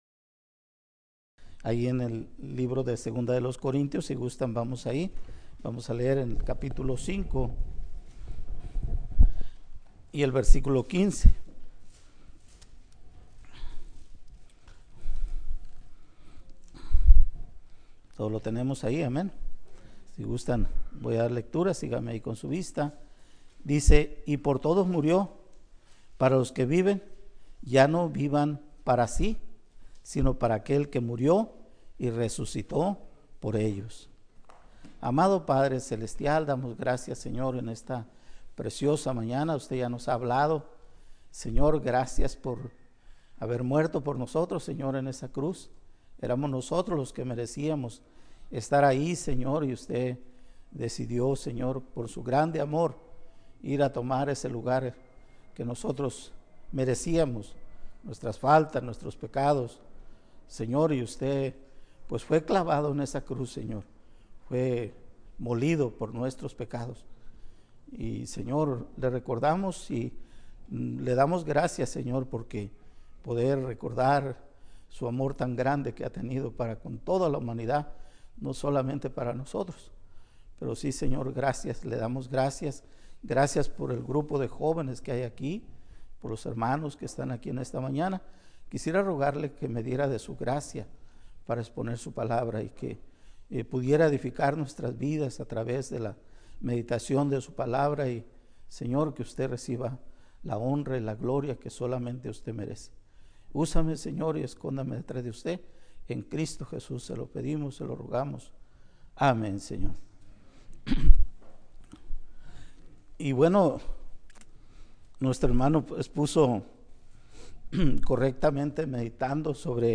Conferencia Bíblica VIII.3 – ¿A quien estás sirviendo?